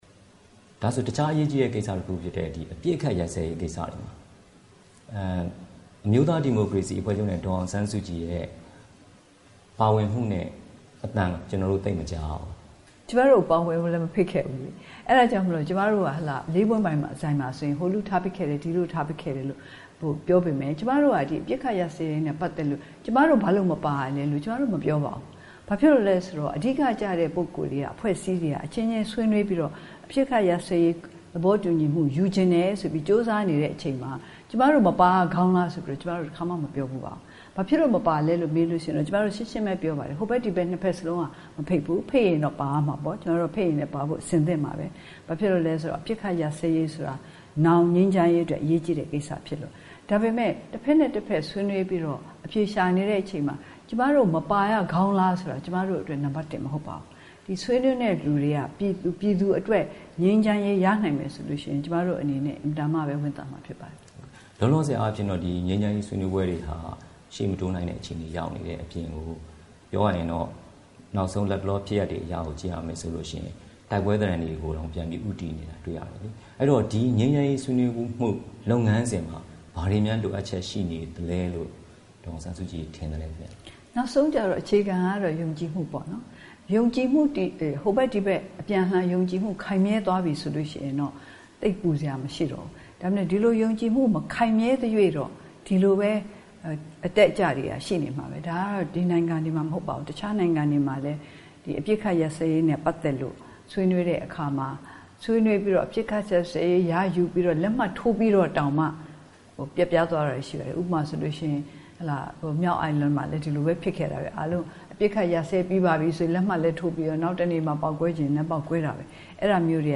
ဒေါ်အောင်ဆန်းစုကြည်နဲ့အင်တာဗျူး (၂)